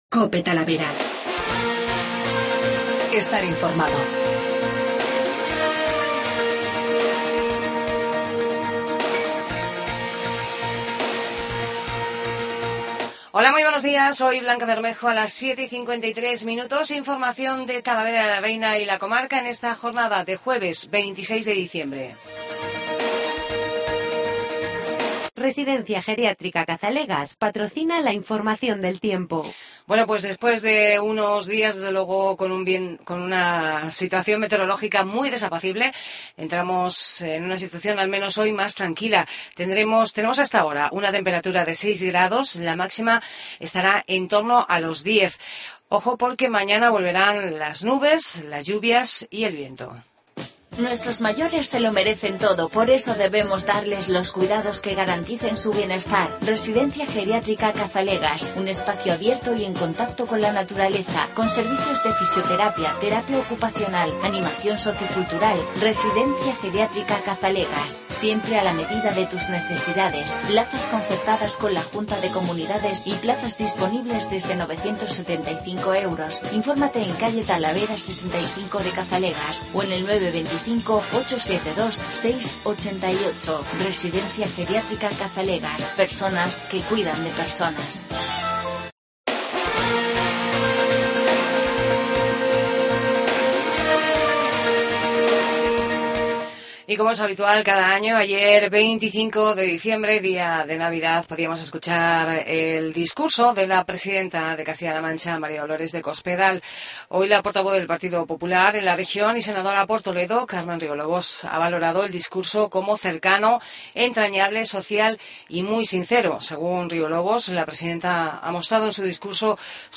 INFORMATIVO MATINAL